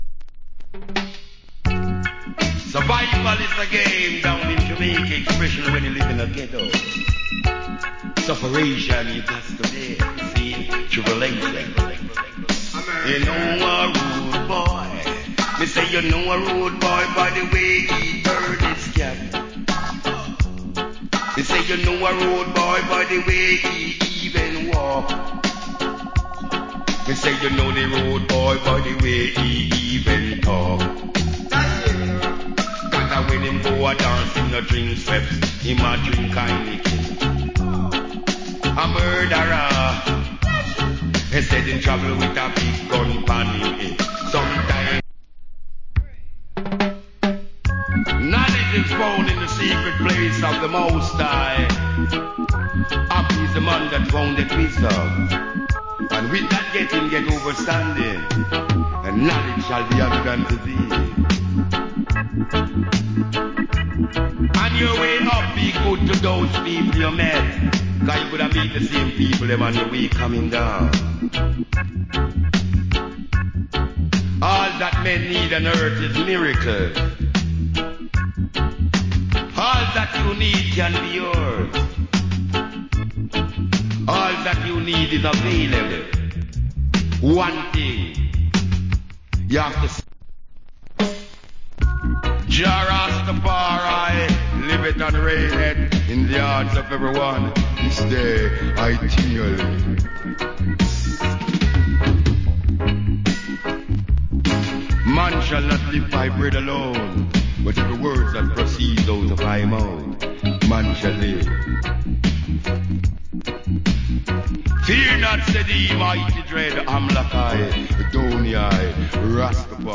Wicked DJ Style.